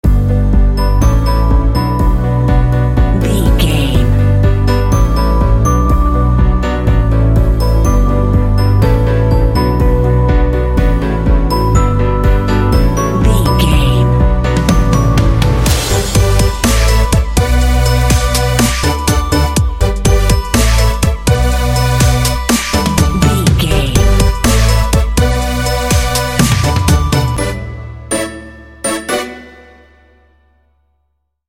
This track makes for a groovy ambient underscore.
Uplifting
Aeolian/Minor
bright
joyful
piano
electric piano
drums
synthesiser
techno